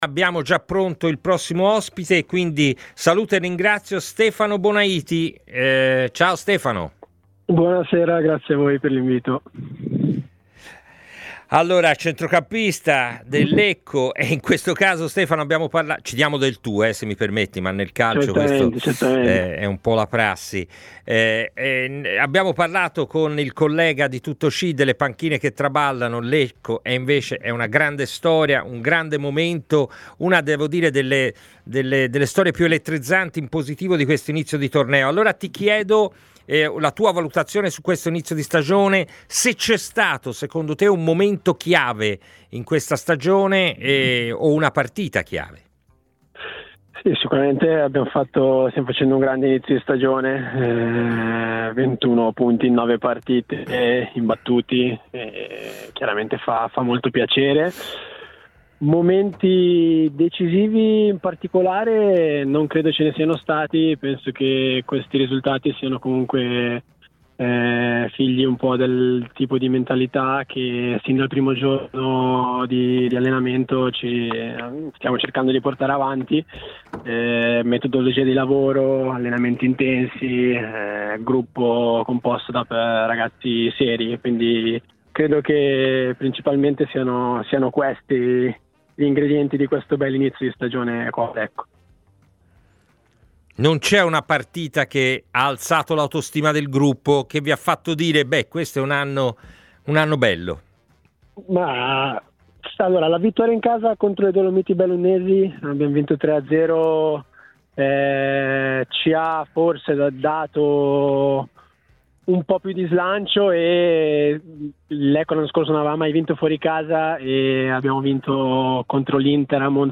A Tutta C', trasmissione in onda su TMW Radio e Il 61